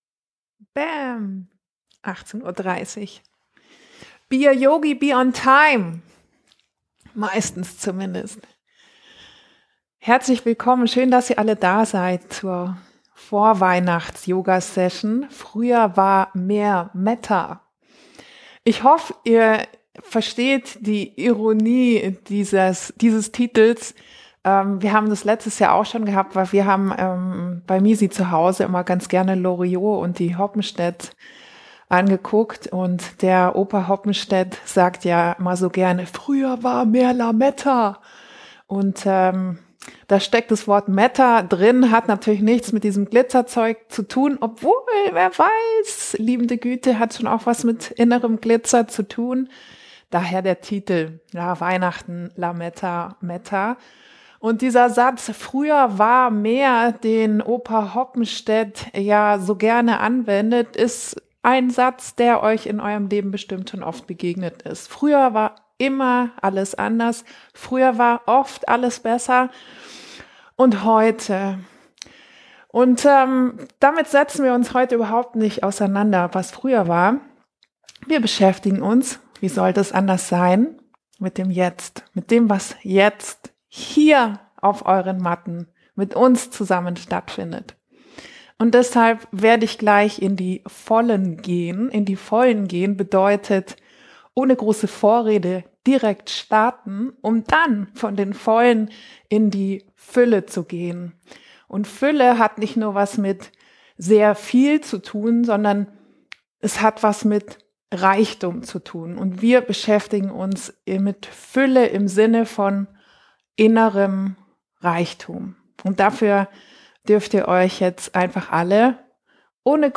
Living Room Session am 23. Dezember 2021 um 18:30 Uhr
Wir waren wieder live für euch mit unserer Pre-Christmas-Session!